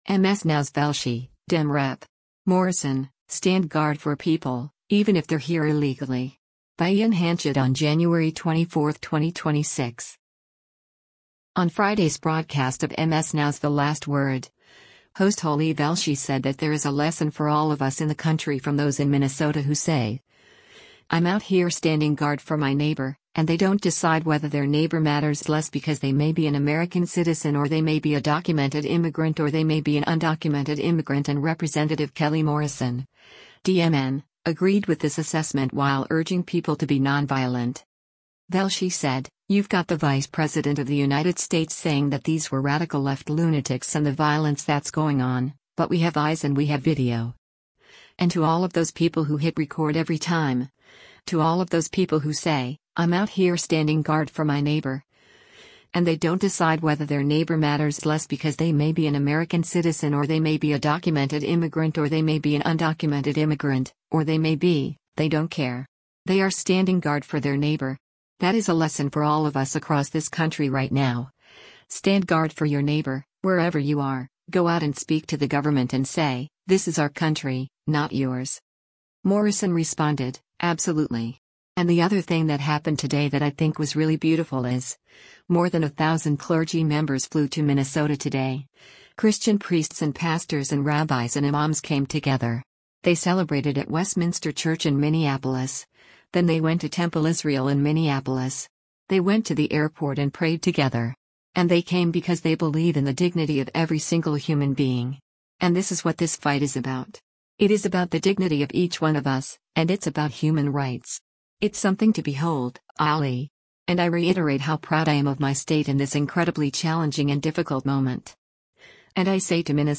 On Friday’s broadcast of MS NOW’s “The Last Word,” host Ali Velshi said that there “is a lesson for all of us” in the country from those in Minnesota who say, “I’m out here standing guard for my neighbor, and they don’t decide whether their neighbor matters less because they may be an American citizen or they may be a documented immigrant or they may be an undocumented immigrant” and Rep. Kelly Morrison (D-MN) agreed with this assessment while urging people to be nonviolent.